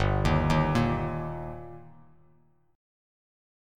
Gm#5 chord